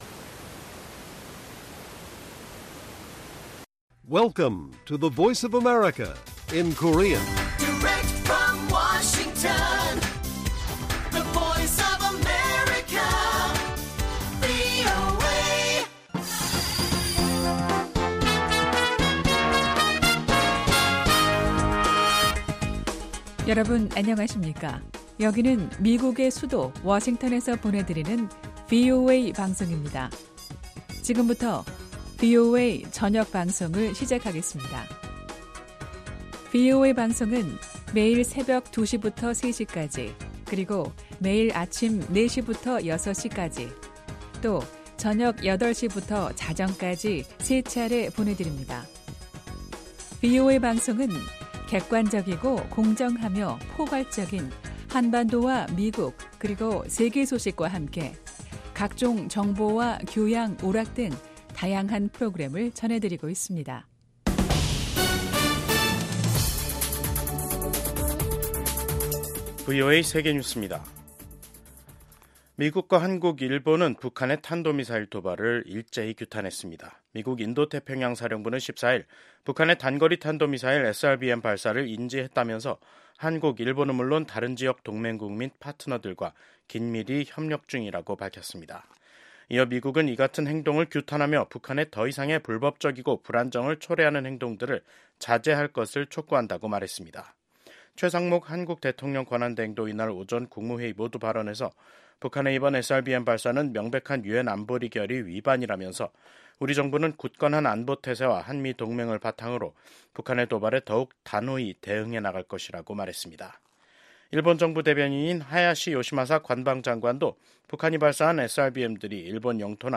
VOA 한국어 간판 뉴스 프로그램 '뉴스 투데이', 2025년 1월 14일 1부 방송입니다. 북한이 8일만에 또 다시 탄도 미사일을 동해상으로 발사했습니다. 미국 국방부는 러시아 파병 북한군이 비교적 잘 훈련된 유능한 보병 병력으로, 우크라이나군에 위협이 되고 있다고 밝혔습니다. 미국의 한 전문가는 새로 들어설 미국의 도널드 트럼프 행정부가 혼란 상태에 빠진 한국 정부와 협력하는 것은 어려울 것으로 전망했습니다.